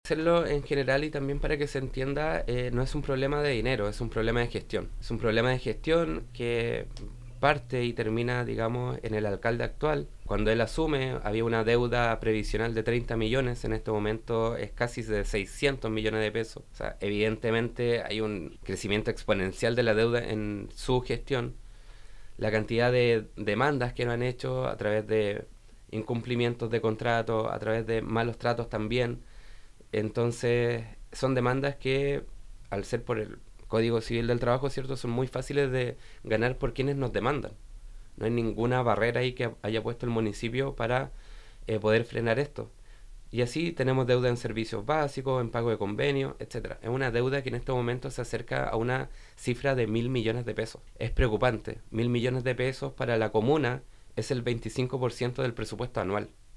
Cortés enfatizó que este problema no radica en un tema económico, sino de una mala gestión, donde la deuda previsional asciende a 600 millones de pesos, lo que sumado a otros montos adeudados alcanzan los mil millones que equivale al 25% del presupuesto comunal.